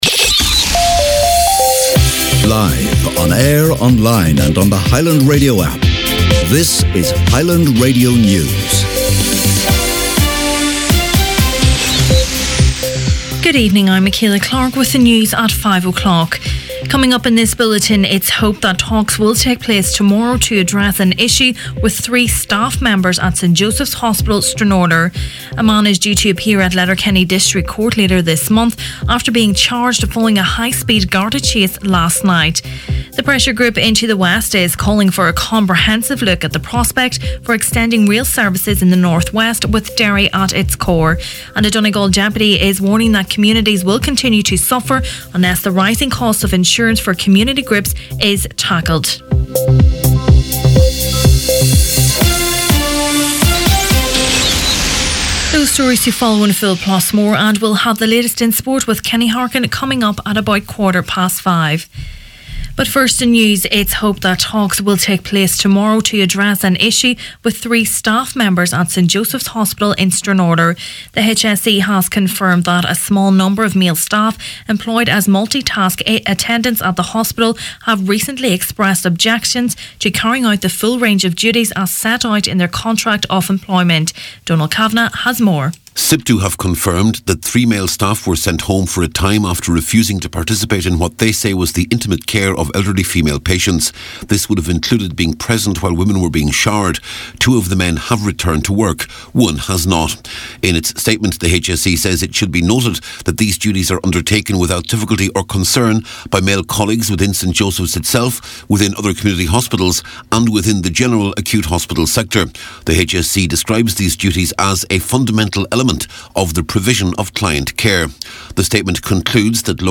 Main Evening News, Sport and Obituaries Thursday 12th April